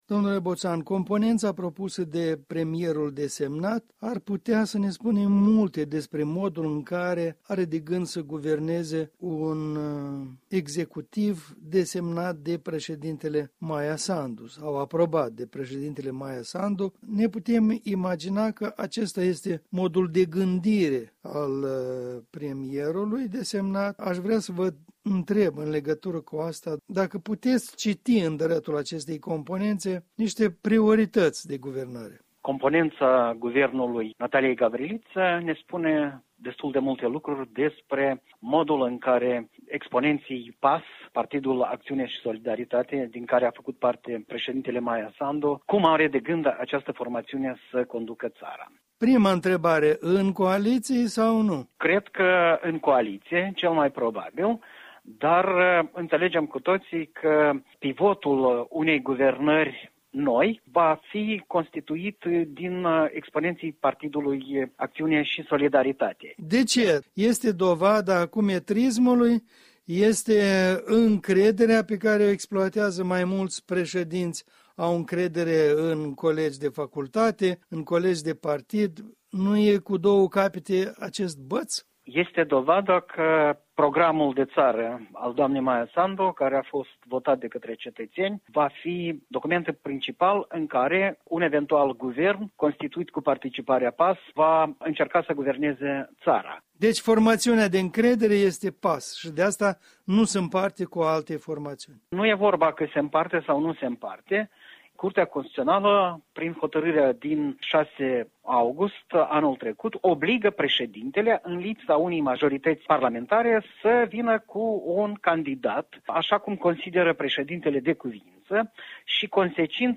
stă de vorbă cu analistul politic